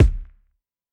KICK IIIII.wav